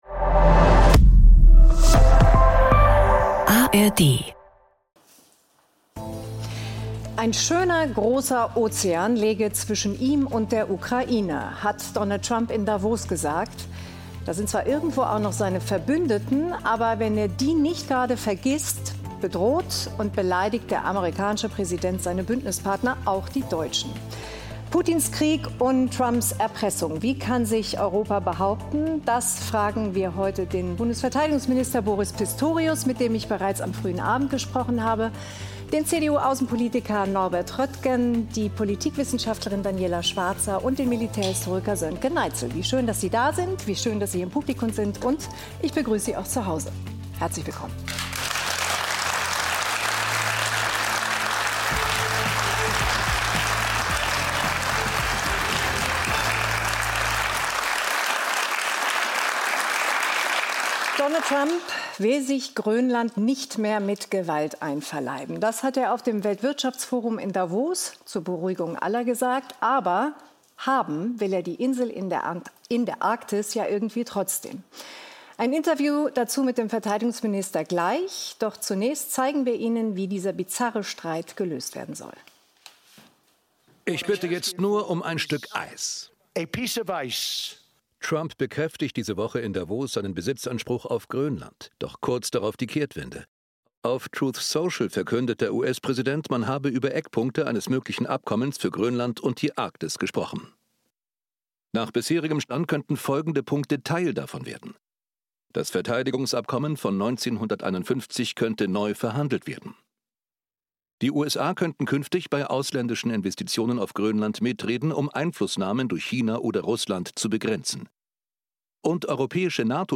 Caren Miosga diskutiert mit ihren Gästen, ob Europa stark genug ist, um zwischen USA, Russland und China zu bestehen, wie realistisch eine militärische Eigenständigkeit Europas ist und ob all das reicht, um der Ukraine neue Hoffnung zu geben?